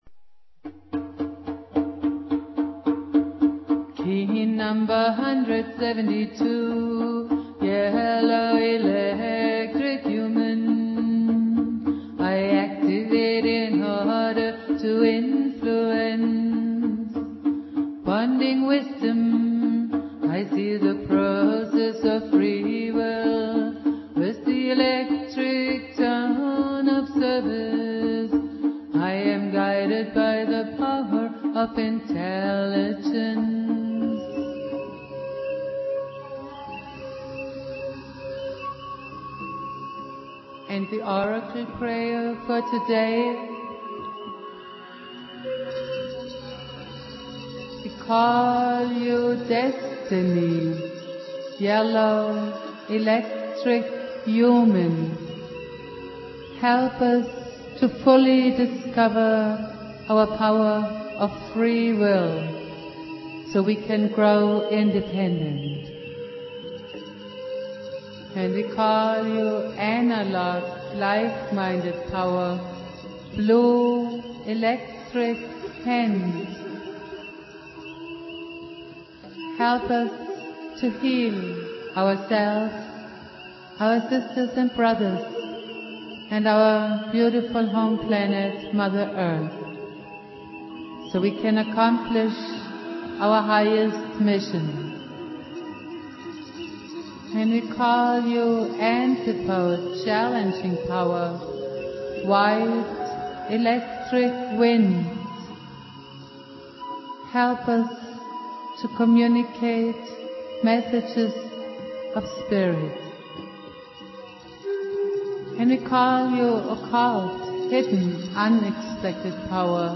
Jose Arguelles - Valum Votan playing flute
Prayer
Jose's spirit and teachings go on Jose Argüelles playing flute.